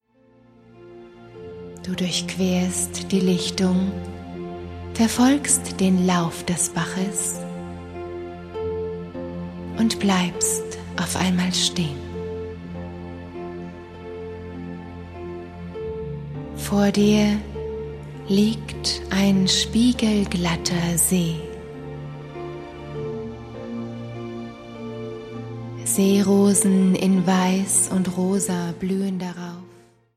Vier Fantasiereisen zum Abschalten und Krafttanken
Die Meditationen auf dieser CD sind Entspannung pur.